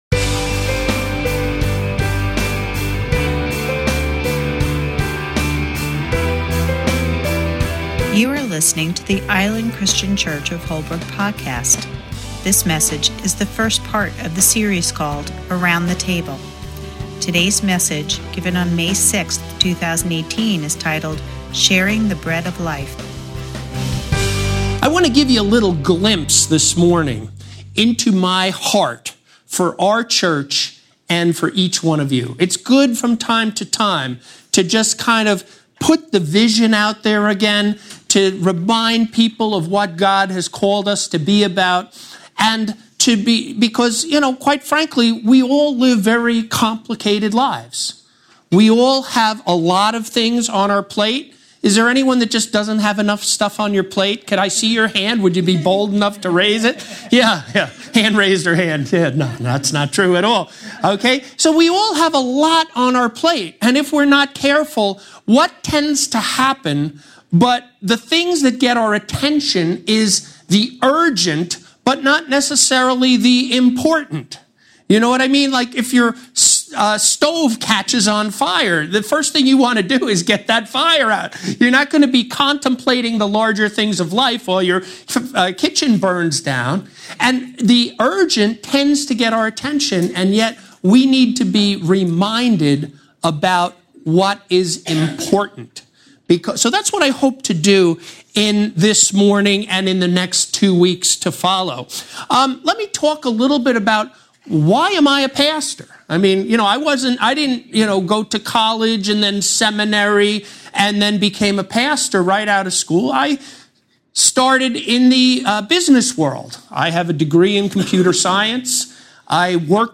Play Rate Listened List Bookmark Get this podcast via API From The Podcast This is the weekly Podcast home of our messages from Island Christian Church-Holbrook meeting at 10 Peachtree Court in Holbrook at 10AM each Sunday Morning.